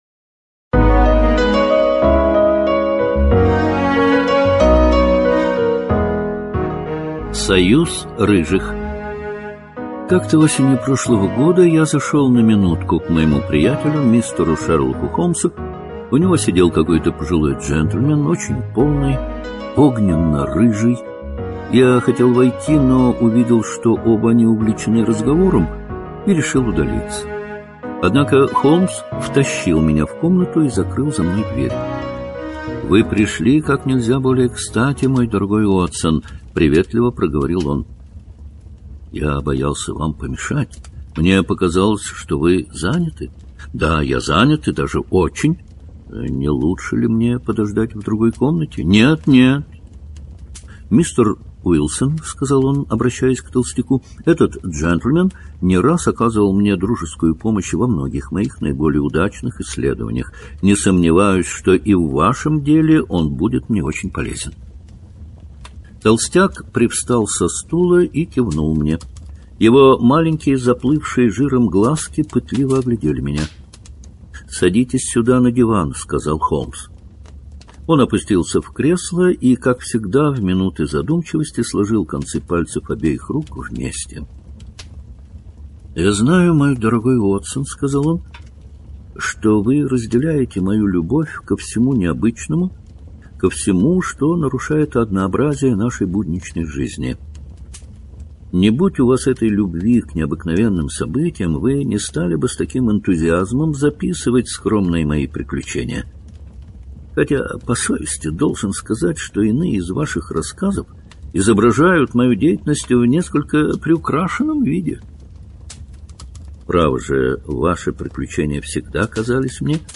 Союз рыжих — слушать аудиосказку Артур Конан Дойл бесплатно онлайн